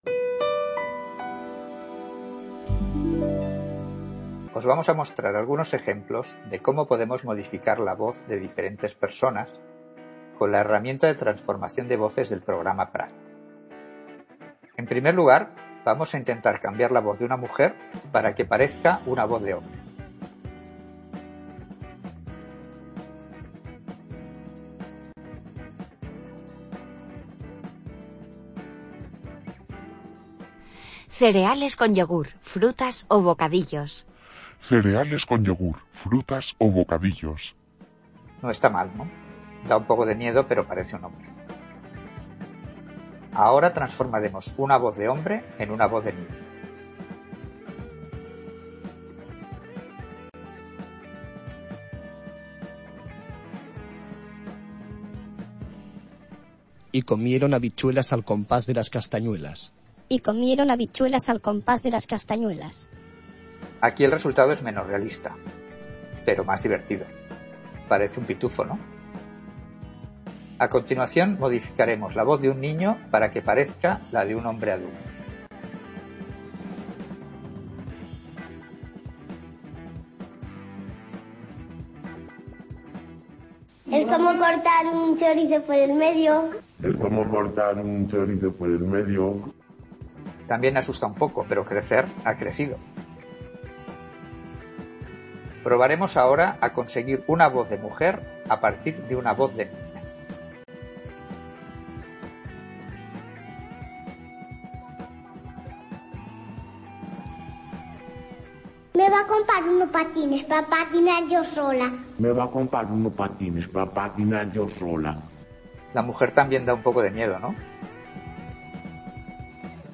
Demostración transformación de voces.